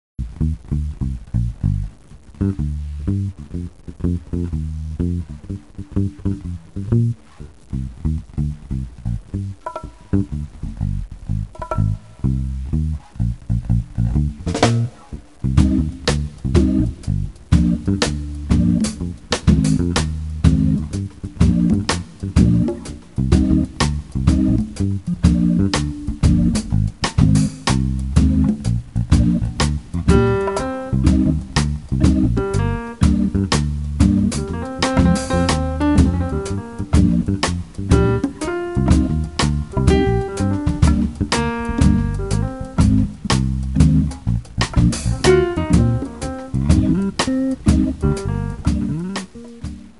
and electric bass
percussion